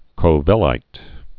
(kō-vĕlīt, kōvə-līt)